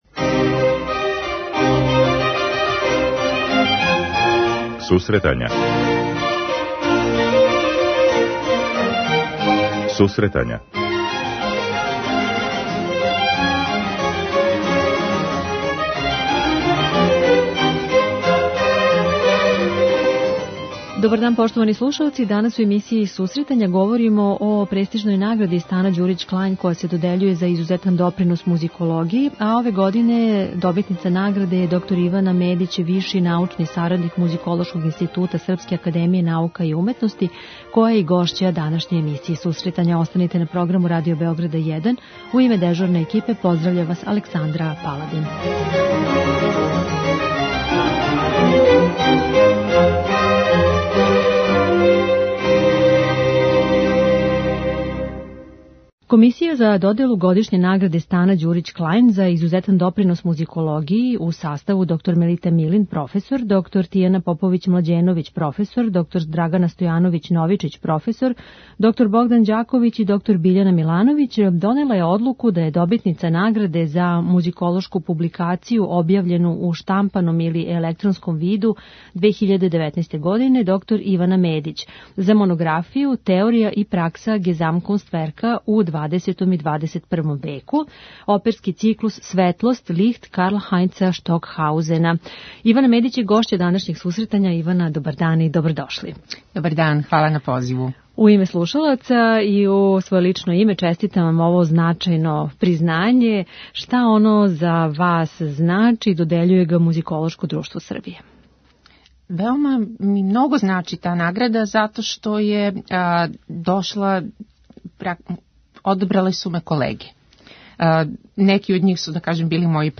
Књигу ћемо представити у разговору